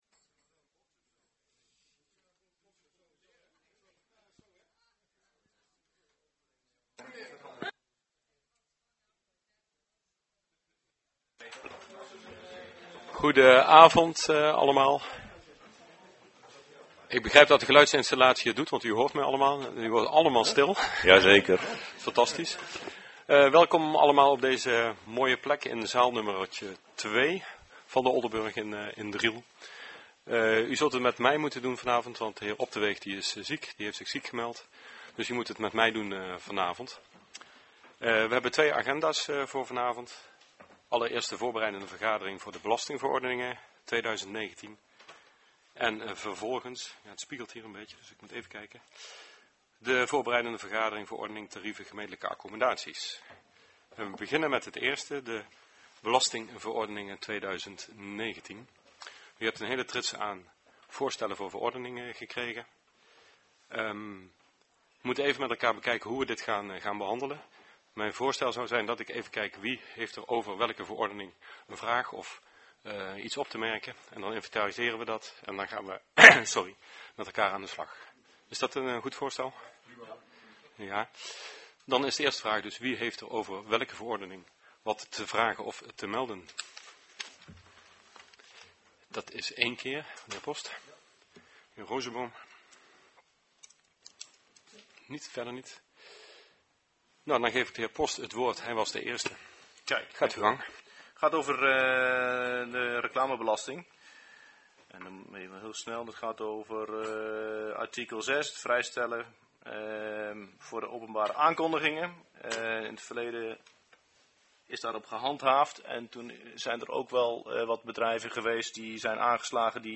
Locatie De Oldenburg, Driel Voorzitter dhr. H. van den Moosdijk
Voorbereidende vergadering Belastingverordeningen 2019